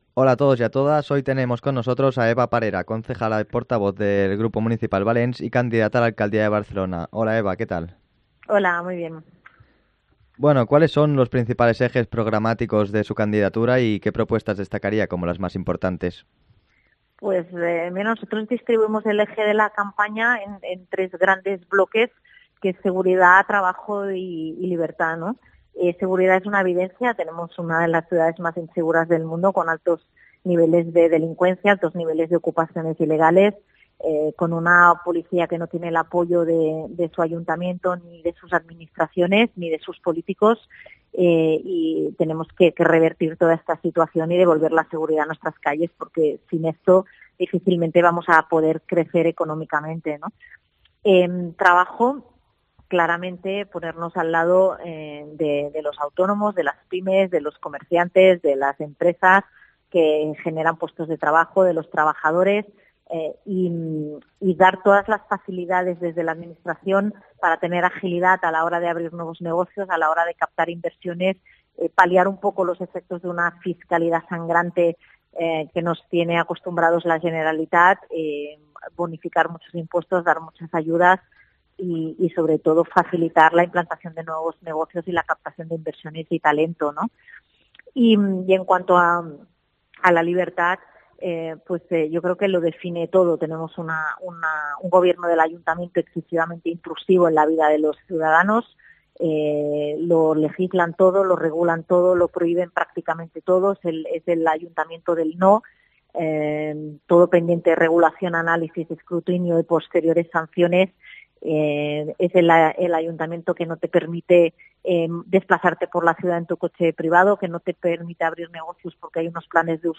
Entrevista a Eva Parera, candidata de Valents a la alcaldía de Barcelona